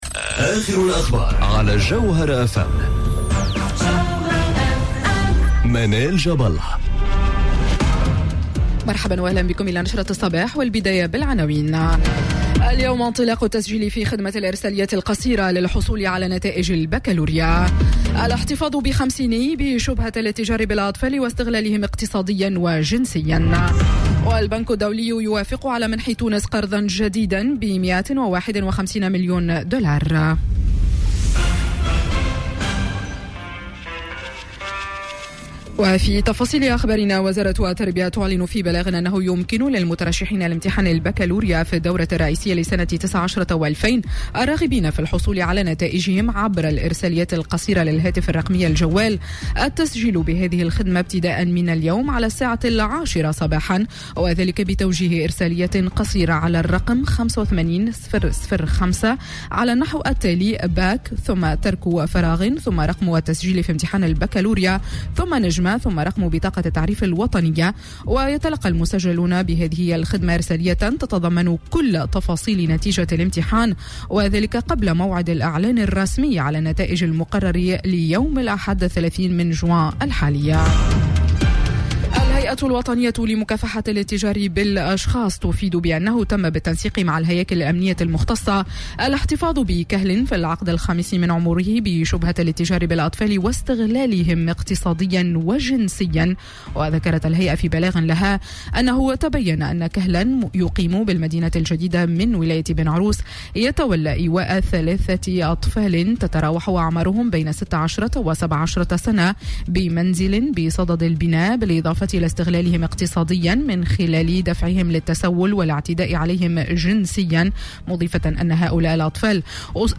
نشرة أخبار السابعة صباحا ليوم الثلاثاء 25 جوان 2019